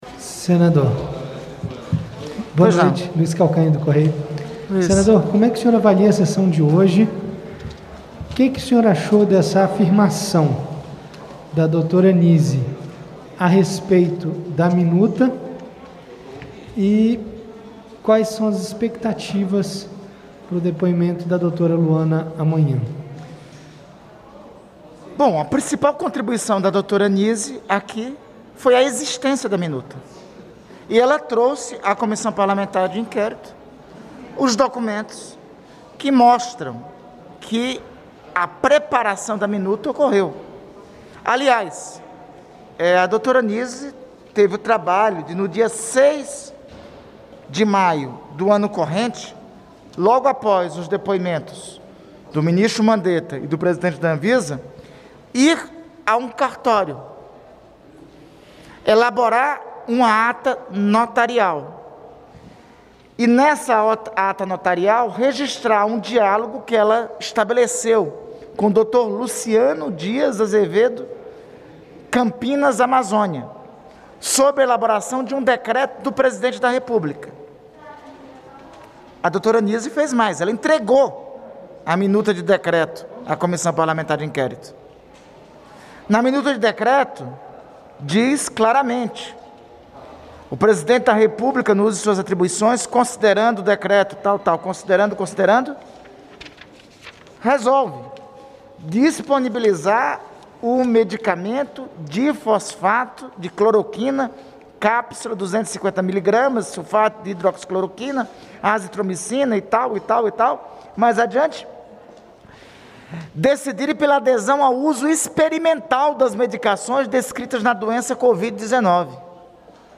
Entrevista coletiva com o vice-presidente da CPI da Pandemia, Randolfe Rodrigues
O vice-presidente da CPI da Pandemia, senador Randolfe Rodrigues (Rede-AP), concedeu entrevista coletiva nesta terça-feira (1º) e falou sobre o depoimento da médica Nise Yamaguchi.